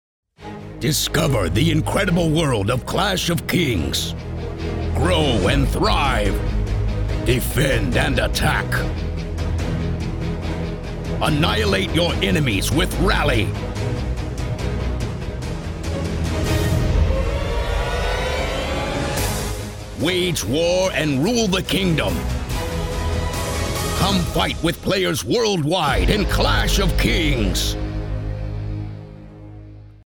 Male
Movie Trailers